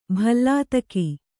♪ bhallātaki